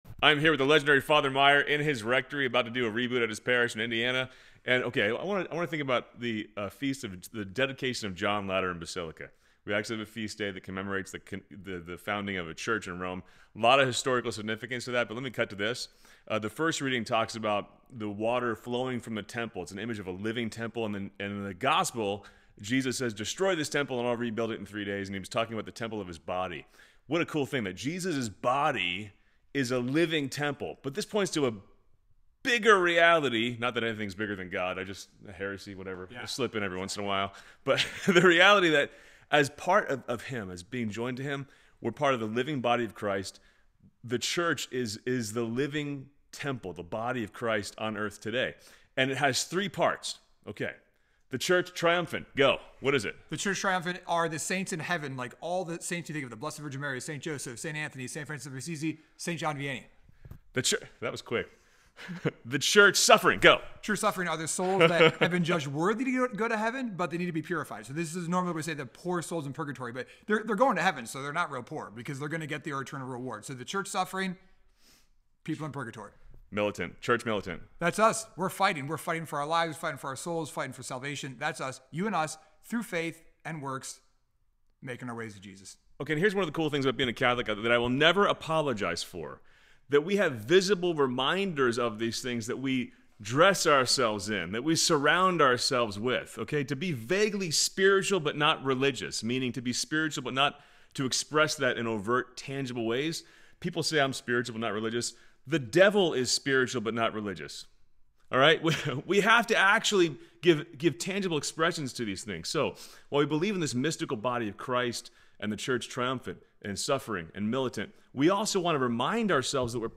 In this on-the-road reflection